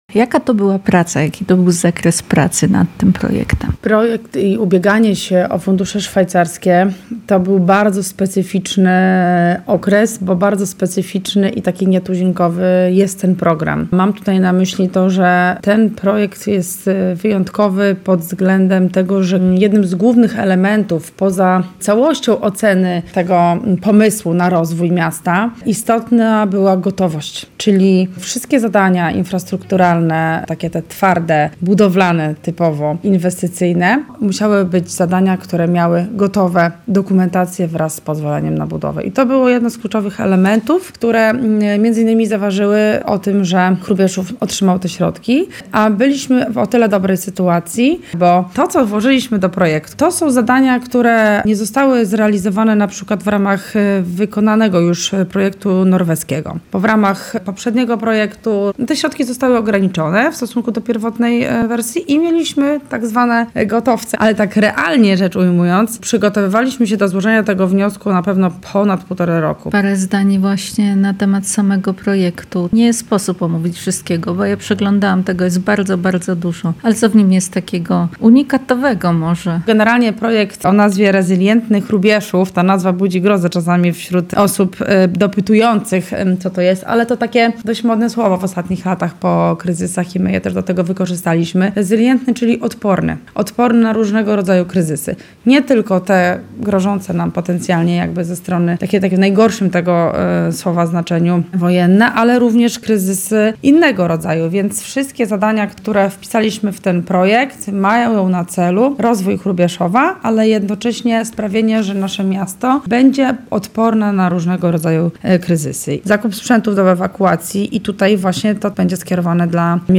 O zagrożeniach, wyzwaniach przyszłości, ale też o echach wakacyjnego referendum samorządowego rozmawiamy z burmistrz najdalej wysuniętego na wschód miasta w Polsce, Martą Majewską.